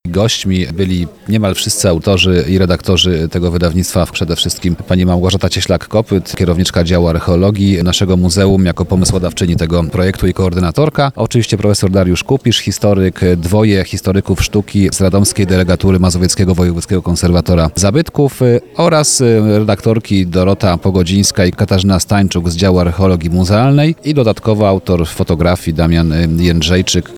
W Muzeum im. Jacka Malczewskiego w Radomiu odbyła się promocja albumu, z udziałem jego autorów.